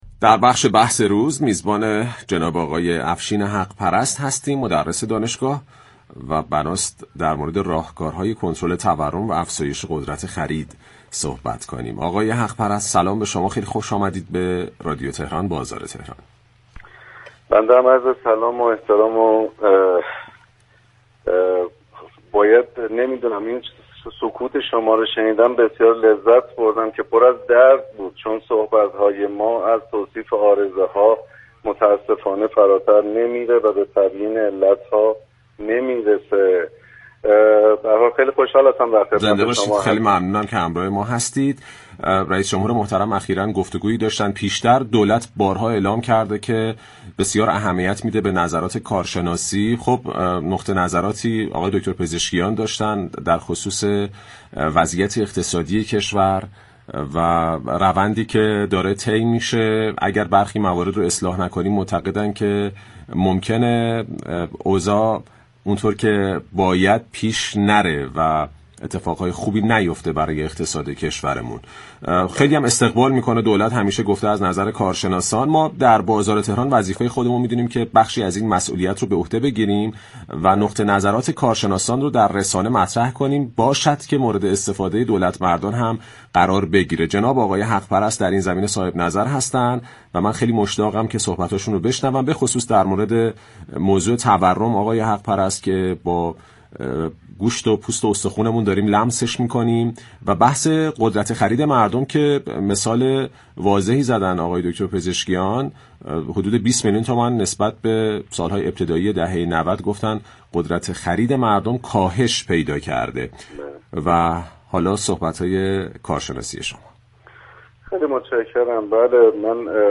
در گفت و گو با «بازار تهران» اظهار داشت